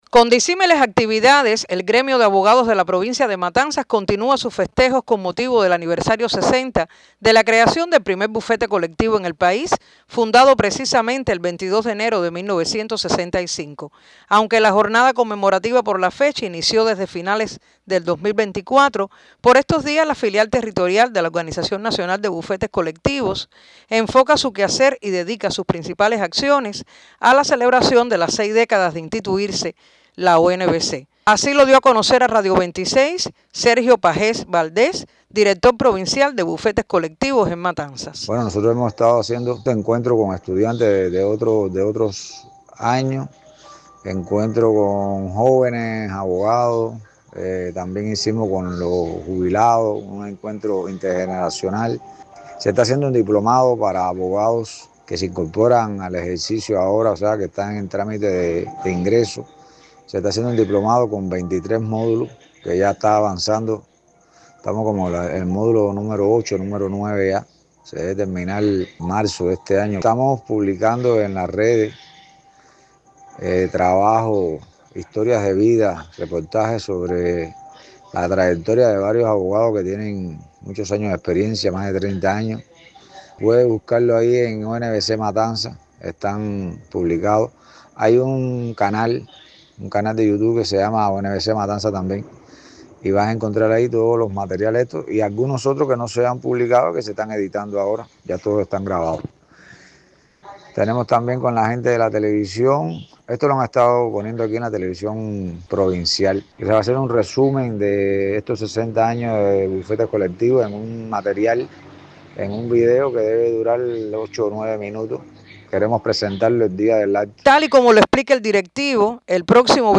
Entrev-Aniv-Bufetes-Colectivos-.1.mp3